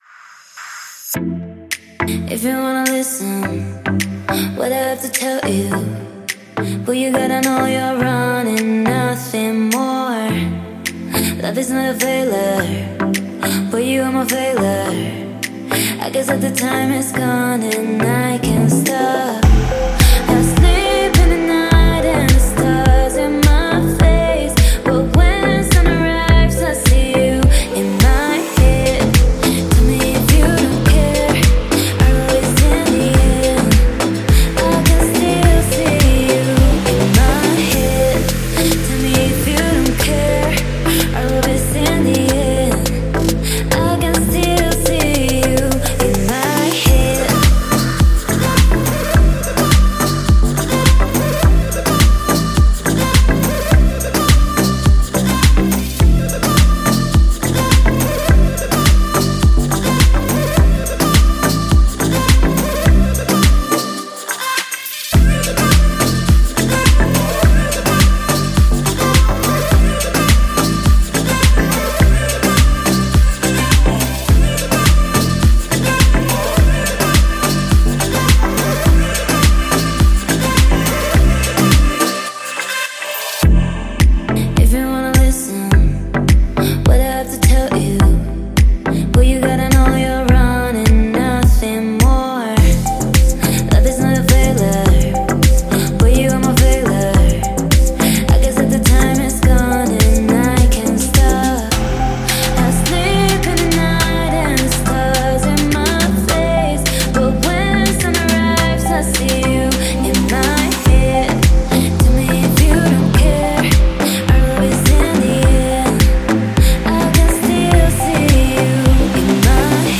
Я не зло употребляю сжатием,так,легкое сглаживание,чтоб было целостное звучание..
В поп музыке?
Это не финальный трек,он еще в работе..